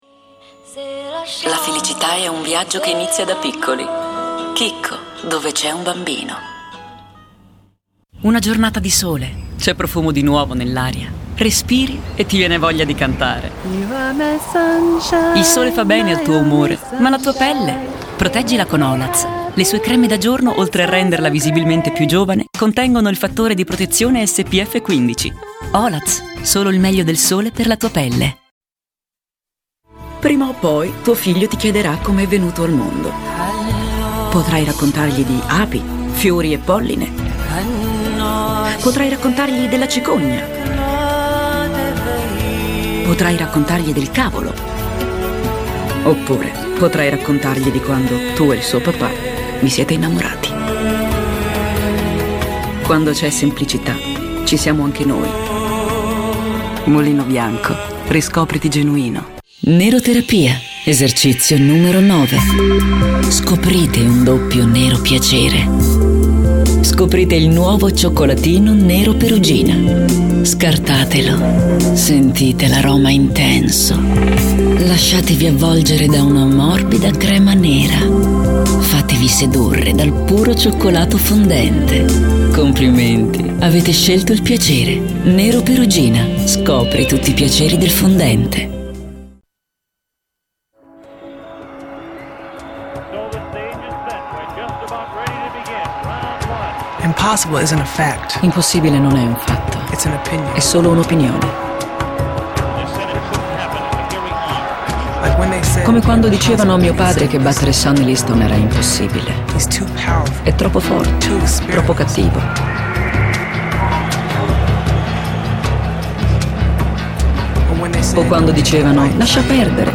COMMERCIALS
Miscellanea | Spot Shesir | Spot Geo Telecom Grattatel | Spot Erg Diesel One | Spot Morellato | Spot Nero Perugina | Spot Oil of Olaz | Spot Nero Perugina | Spot Nero Perugina | Spot